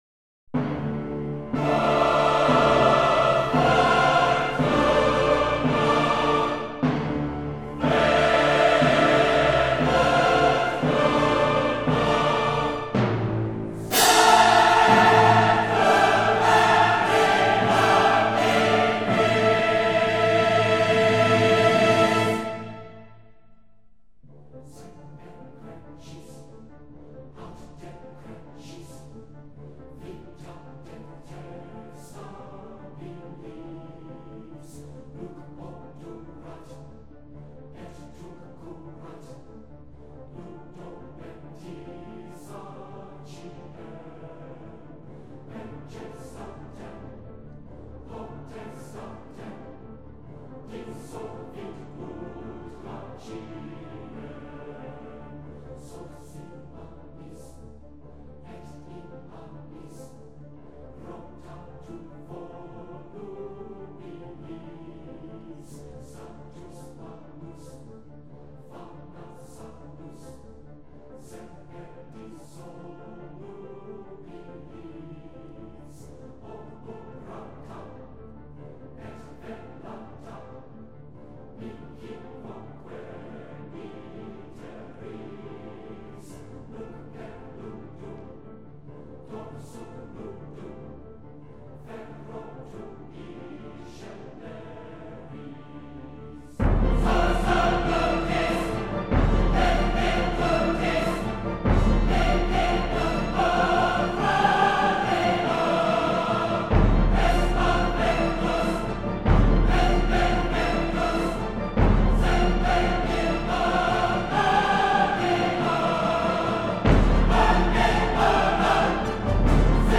那么你应该不会忘记一段雄壮而气势磅礴的合唱
节奏上加重打击乐成分
乐队与唱咏交相辉映；令人震撼的打击乐像是命运的召唤，有着强烈的扣击灵魂的效果。